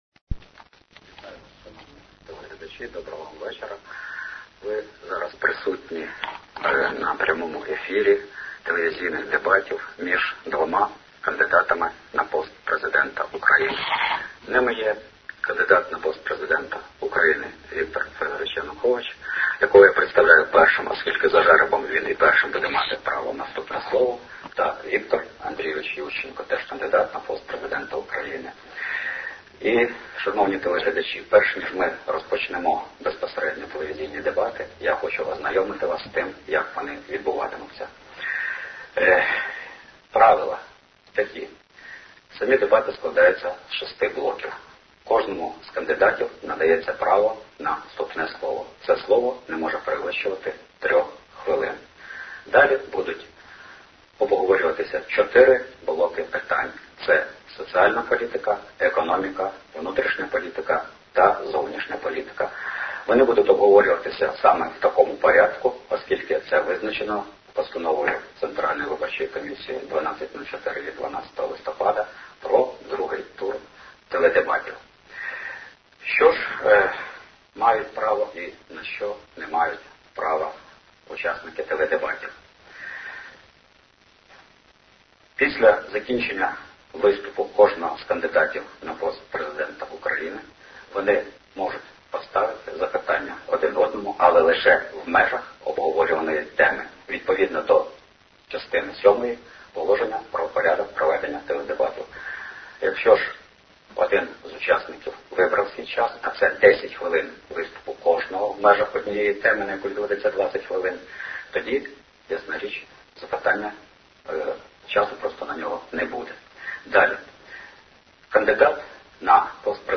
MP3 файл запису дебатів